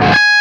LEAD G#4 LP.wav